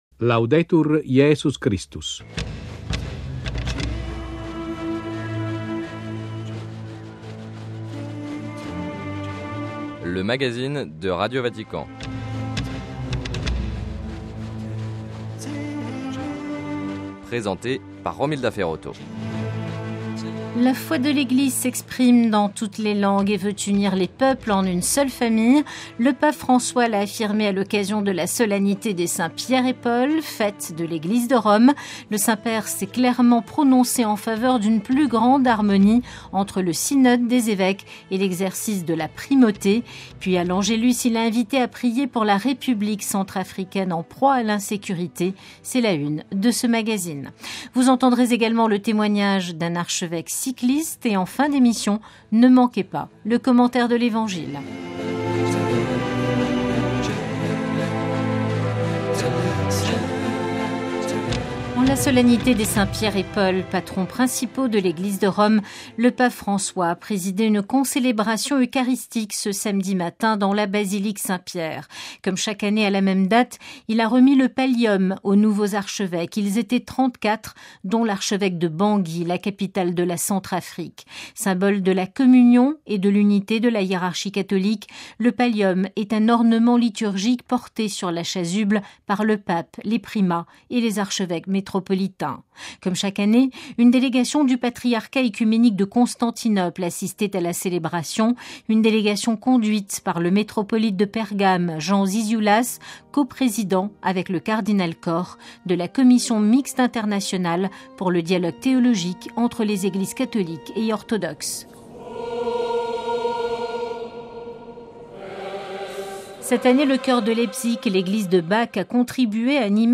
- Vaste campagne de l'Eglise du Canada en faveur de la Syrie. - Centième édition du Tour de France : entretien avec un archevêque cycliste, Mgr André Lacrampe.